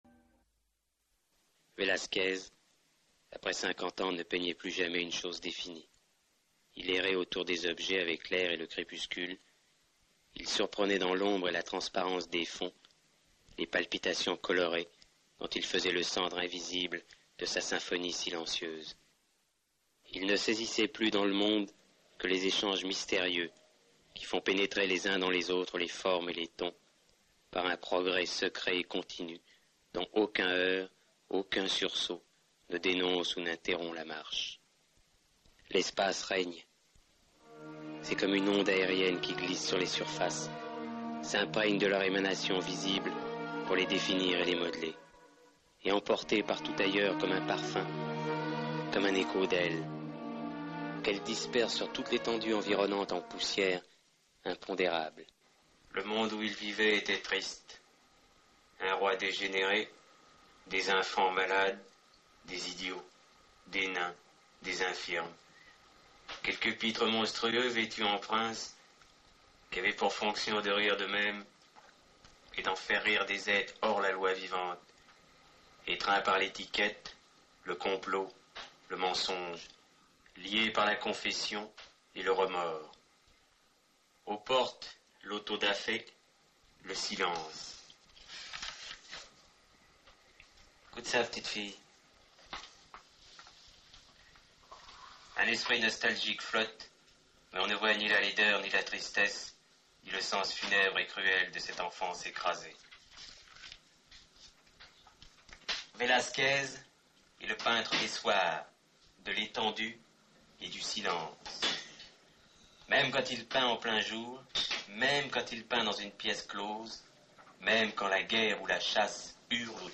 "Pierrot le fou"- Lecture de J.P.Belmondo
Document audio : Lecture de J.P. Belmondo